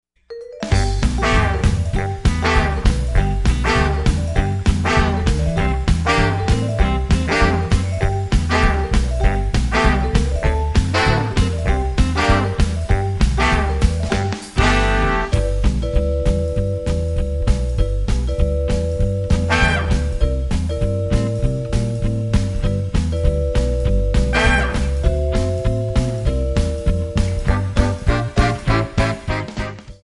Backing track Karaoke
Pop, Jazz/Big Band, 1990s